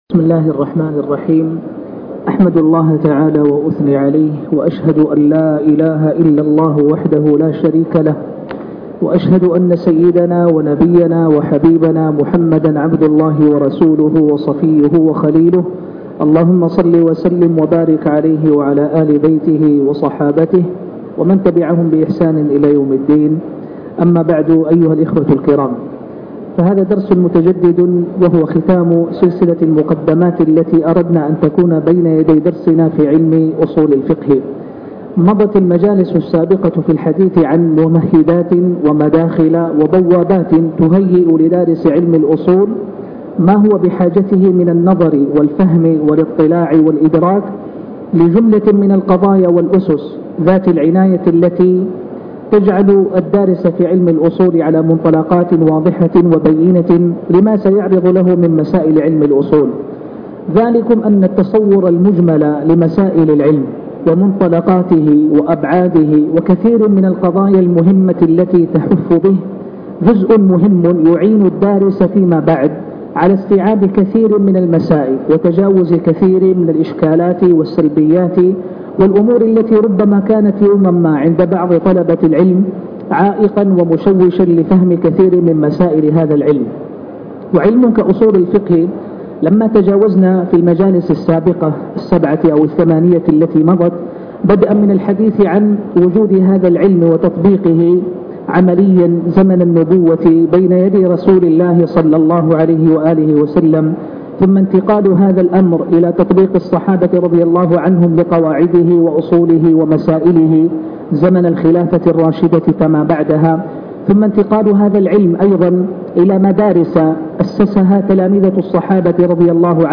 الدرس الثامن (المذاهب العقدية في اصول الفقه ) مدخل لدراسة اصول الفقه